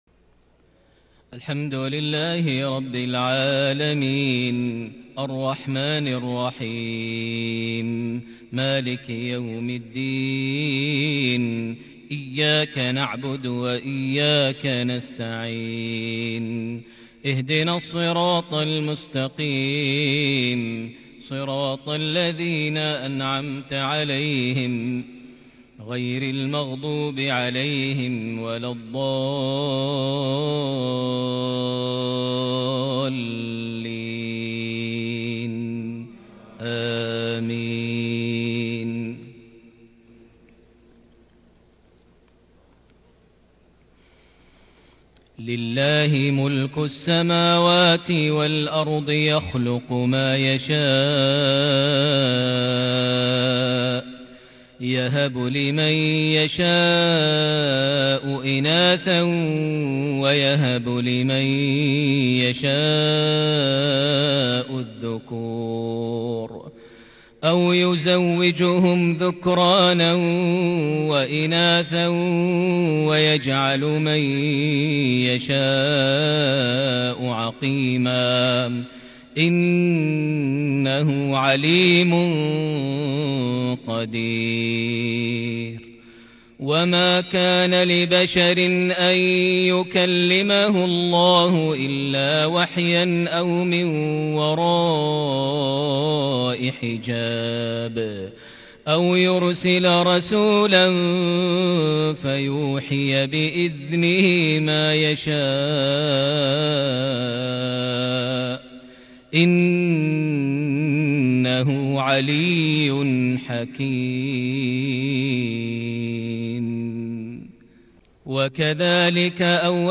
صلاة المغرب ١٦ جماد الآخر ١٤٤١هـ خواتيم سورة الشورى > 1441 هـ > الفروض - تلاوات ماهر المعيقلي